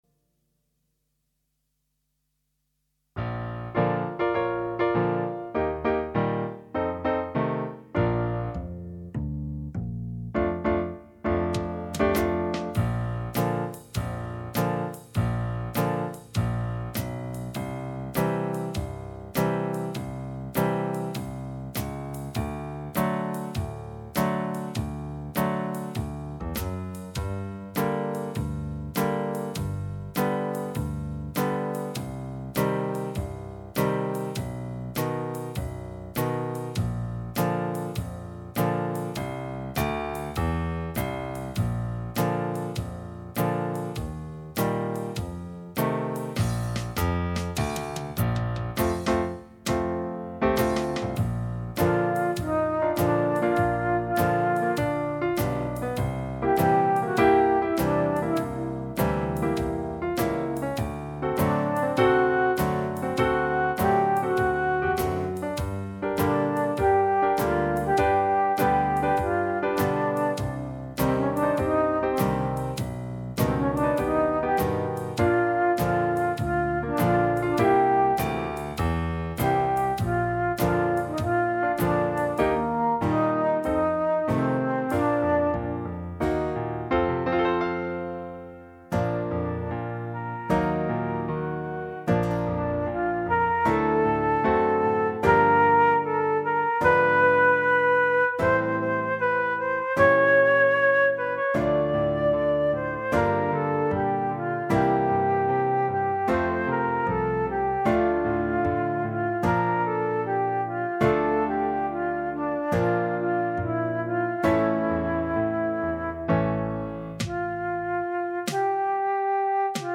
minus Rhythm